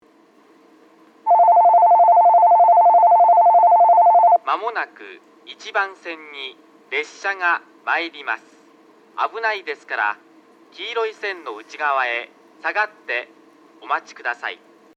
1番のりば接近放送　男声
放送はかなり特殊で、ベルはJR九州標準のベルでなく、ごく普通な電子ベルが流れ、「1番のりば」ではなく、「1番線」と放送されます。
放送はラッパ型から流れ、並列して設置されているクリアホーンからは遠隔の予告放送が流れます。